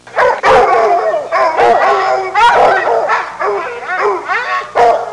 Hounds With Scent Sound Effect
hounds-with-scent.mp3